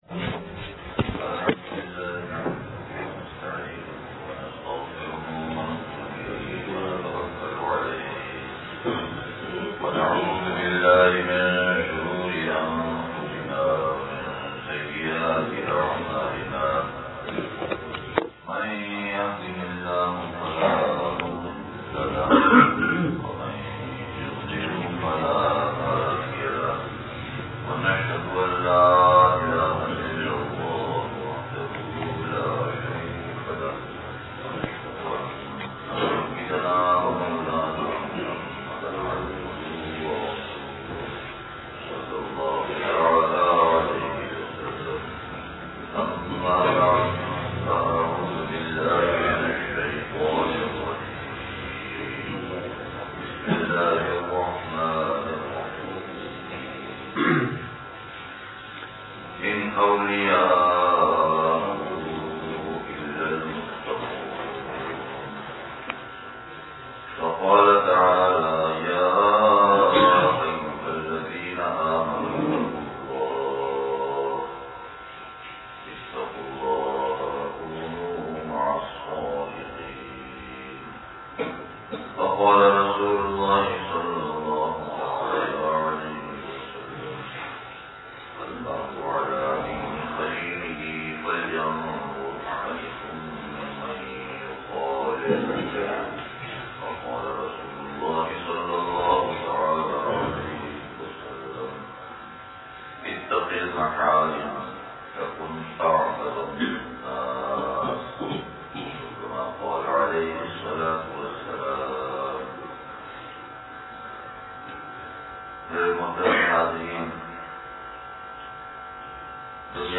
مسجد اقصیٰ منوآباد نواب شاہ سندھ (بعد فجر بیان)